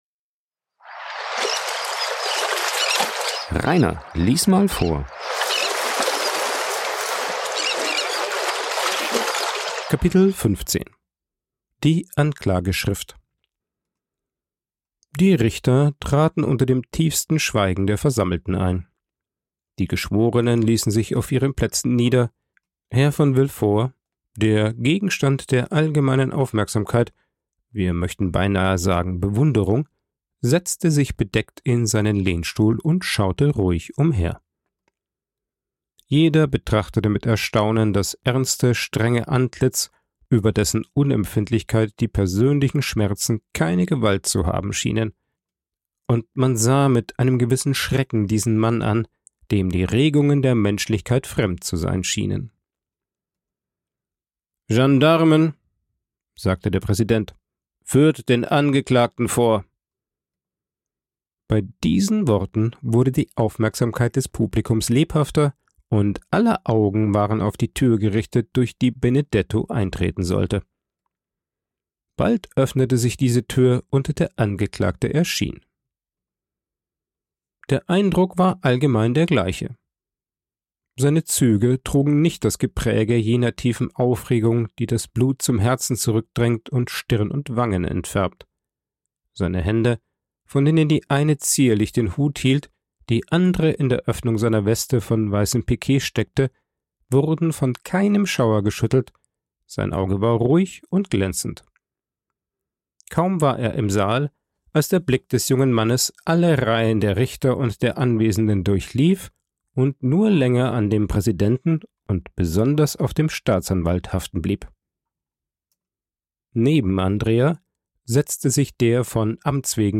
Als Grund gibt er an, seinen Vater nie gekannt zu haben ... bis vor wenigen Tagen als ihm enthüllt wurde, dass er der Sohn des Staatsanwaltes Villefort ist. Vorgelesen
aufgenommen und bearbeitet im Coworking Space Rayaworx, Santanyí, Mallorca.